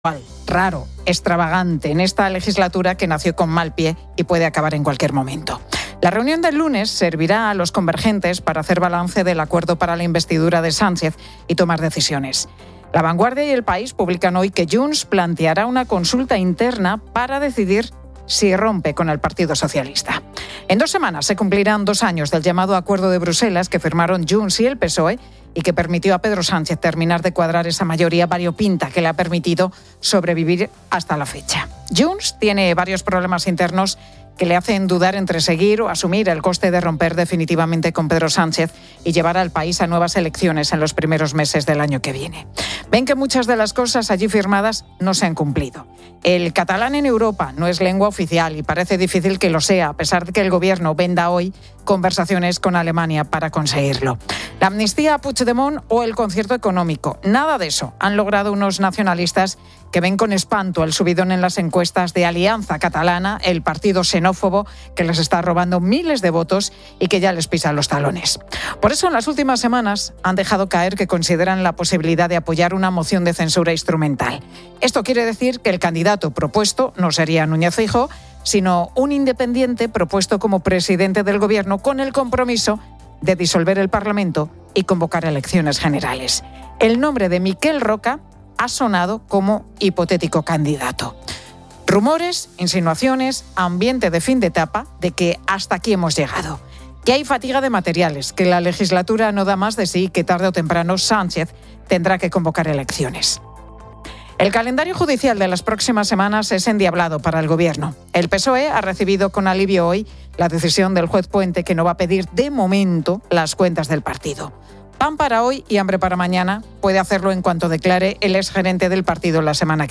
En COPE se analiza la gestión del Partido Popular de Andalucía sobre los cribados de cáncer de mama. Una oyente destaca que su padre, al ser policía, trabajaba a turnos y no tenía una rutina de sueño, lo que afectaba su bienestar. Se plantea si España duerme poco y mal y cómo afecta esto a la salud, el rendimiento y la seguridad.